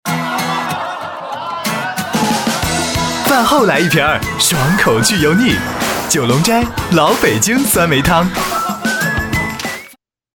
男国106_广告_食品饮料_九龙斋_年轻.mp3